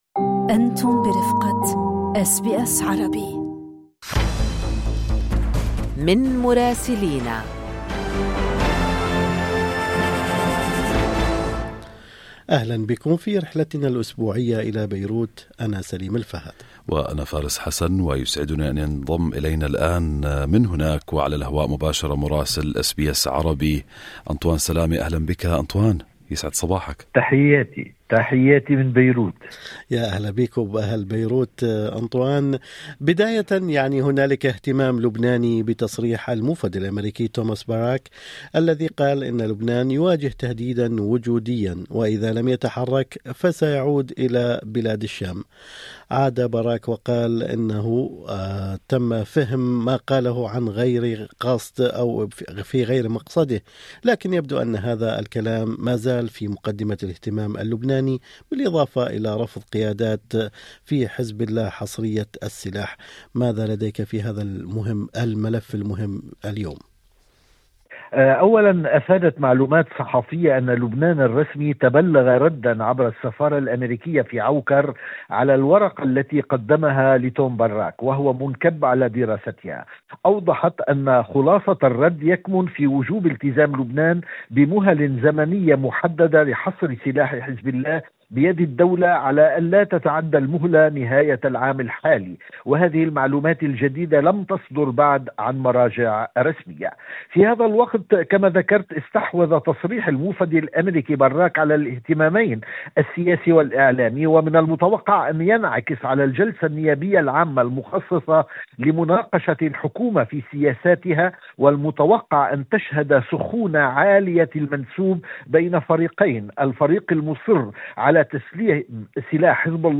تقرير المراسل